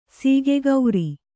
♪ sīge gauri